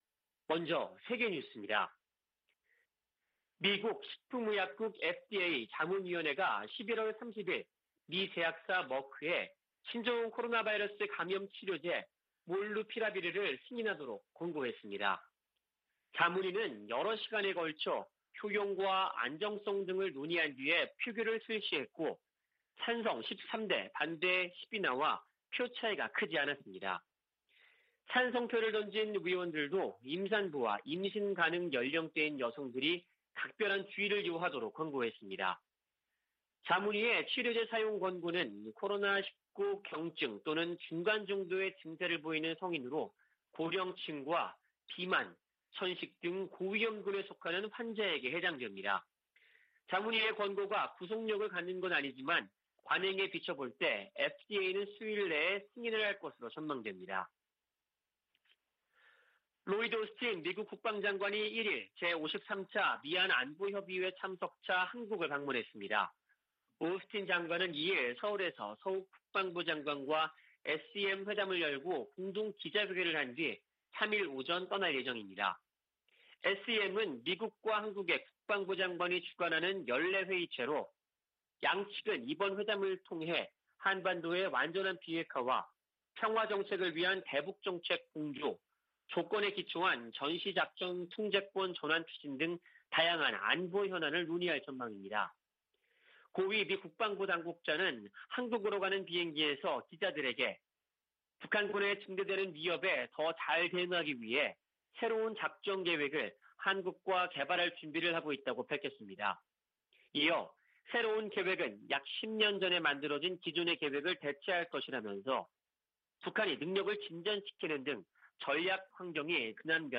VOA 한국어 '출발 뉴스 쇼', 2021년 12월 2일 방송입니다. 11월 중에 재개될 가능성이 거론됐던 북-중 국경 개방이 무산된 것으로 보입니다. 올해는 9년 만에 미국의 대북 독자 제재가 한 건도 나오지 않은 해가 될 가능성이 높아졌습니다. 한국전 실종 미군 가족들이 미국 정부에 유해 발굴 사업을 정치적 사안과 별개로 추진하라고 요구했습니다.